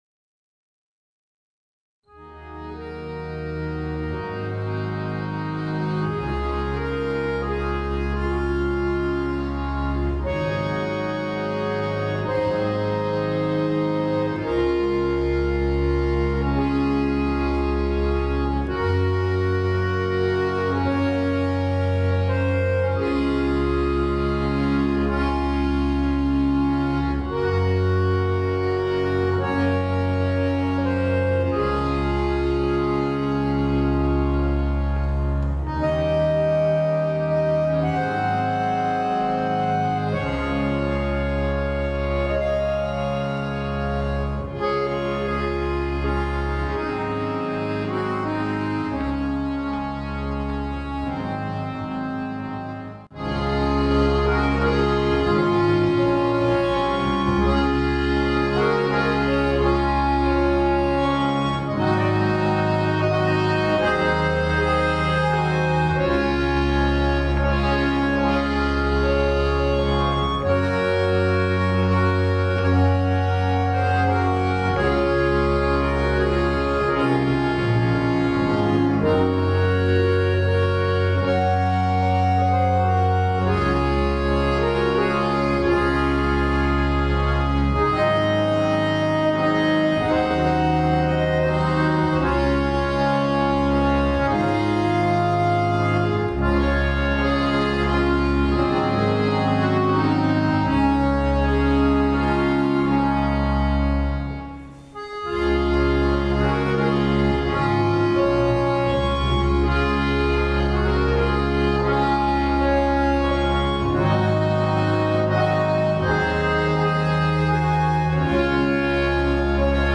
vorgetragen vom HFW Jugendorchester + junge Spieler (Aufnahme in der Probe am 4.12.2024)